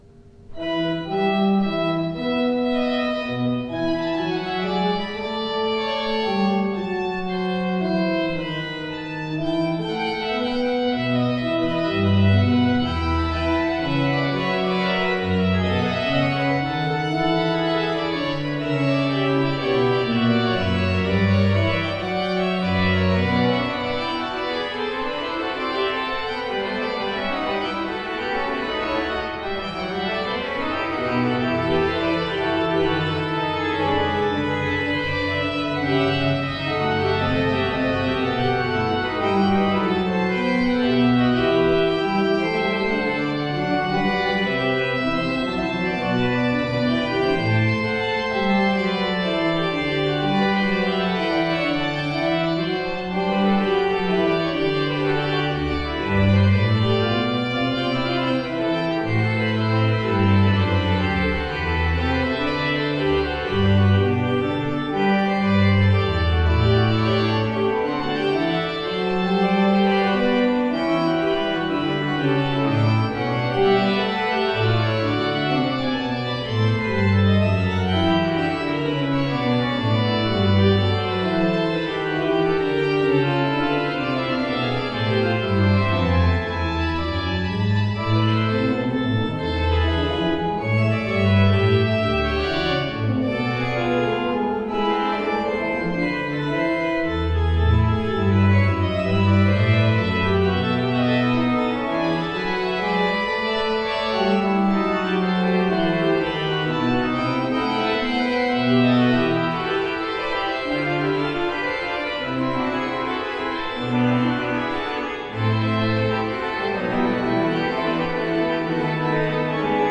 Postludes played at St George's East Ivanhoe 2016
The performances are as recorded on the Thursday evening prior the service in question and are made using a Zoom H4 digital recorder.